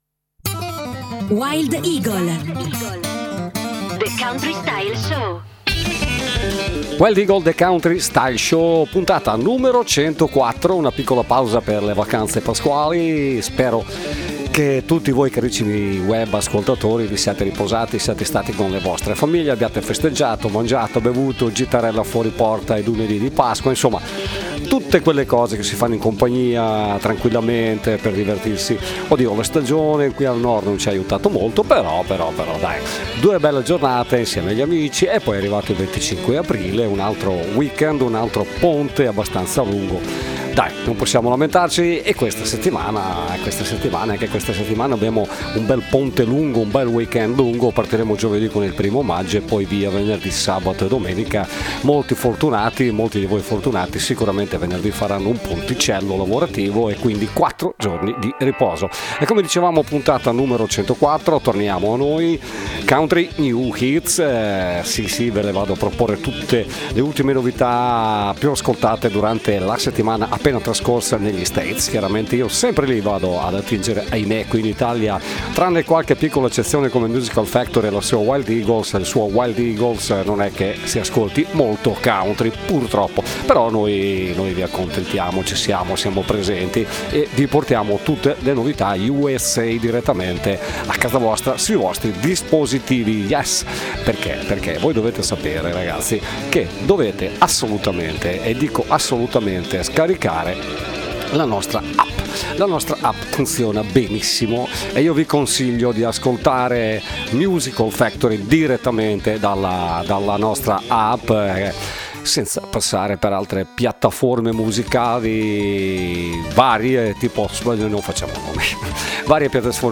All of the newest country songs in one playlist!